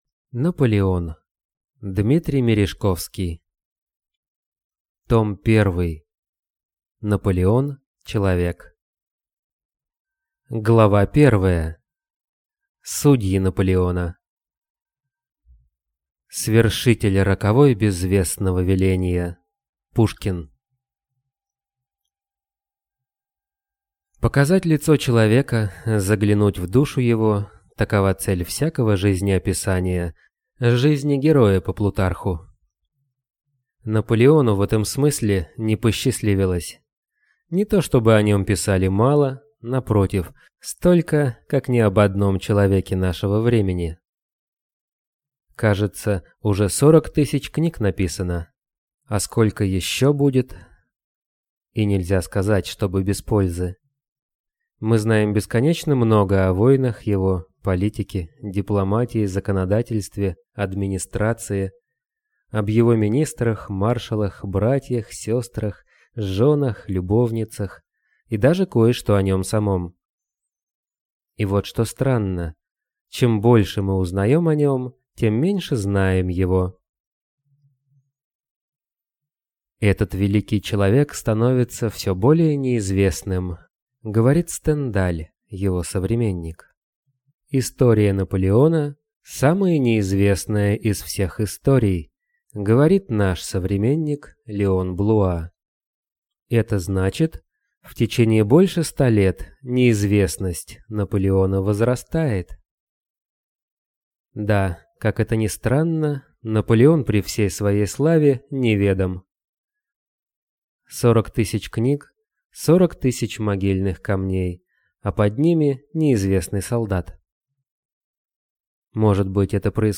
Аудиокнига Наполеон | Библиотека аудиокниг
Прослушать и бесплатно скачать фрагмент аудиокниги